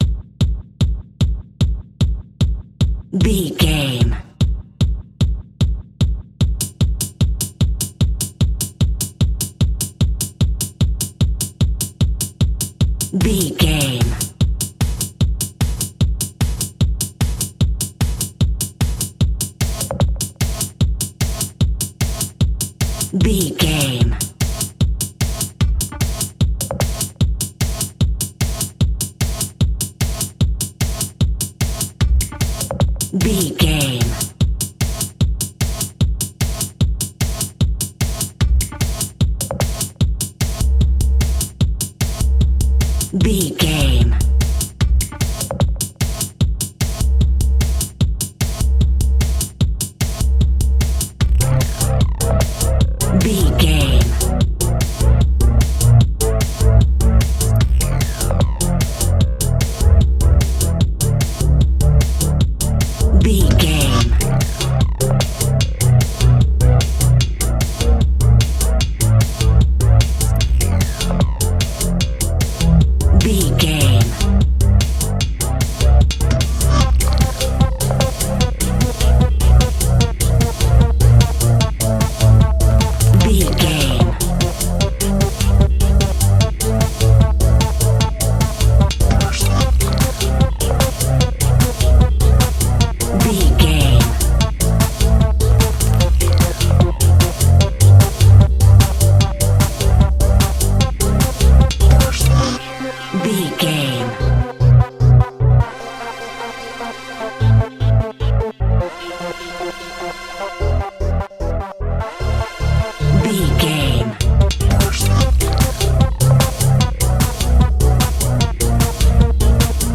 Hard Core Techno.
Fast paced
Ionian/Major
aggressive
powerful
dark
futuristic
driving
energetic
drum machine
synthesiser
strings